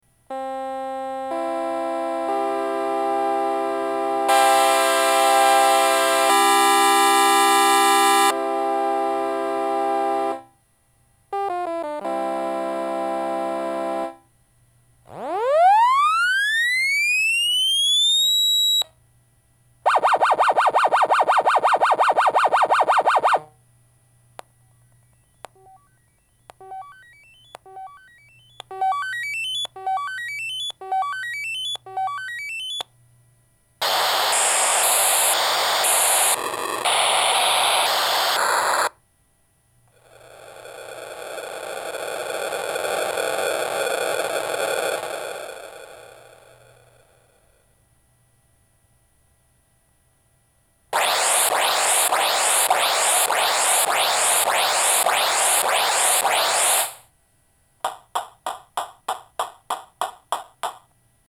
The SID chip supports 3 voices, 4 waveforms (triangle, sawtooth, square and noise), and some advanced features such as ring modulation.
MP3 recording of the sample code